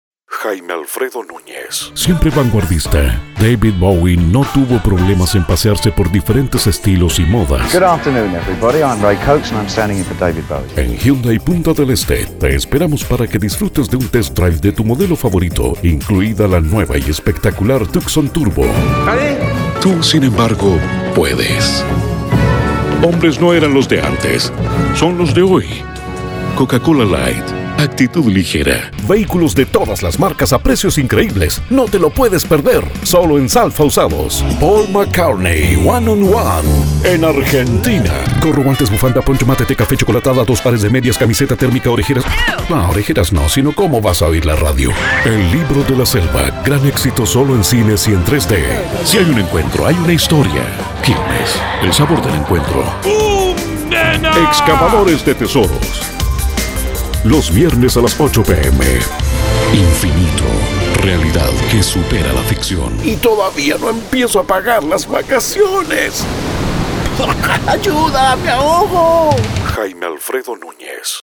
Specialized in Latin American Neutral accent, argentinian uruguayan and also my native chilean. Styles: Interesting and sexy. Elegant, warm and deep for narrations. Energetic and fun to Radio or TV commercial. Smooth and professional for Presentations.
chilenisch
Sprechprobe: Sonstiges (Muttersprache):